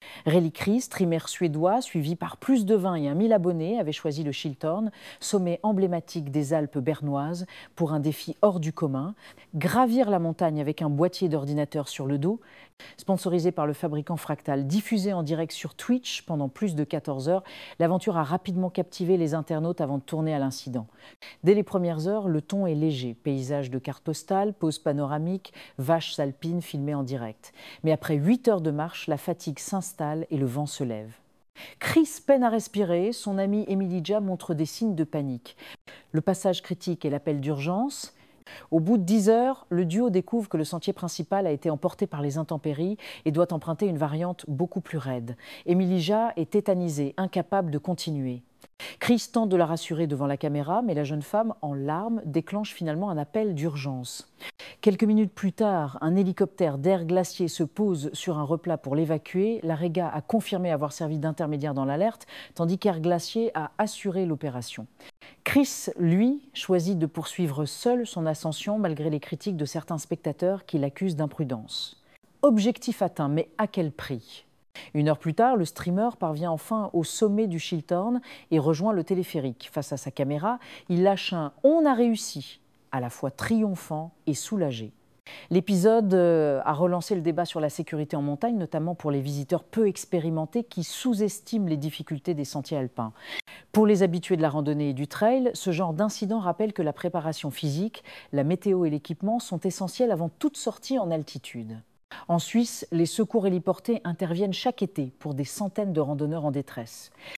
Ecouteur cet article sur cet influenceur secouru dans les Alpes